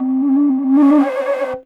Index of /90_sSampleCDs/Best Service ProSamples vol.52 - World Instruments 2 [AIFF, EXS24, HALion, WAV] 1CD/PS-52 AIFF WORLD INSTR 2/WOODWIND AND BRASS/PS MOCENO BASSFLUTE LICKS